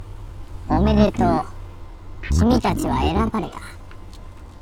Voice Test File
部隊長の台詞のテストファイルです。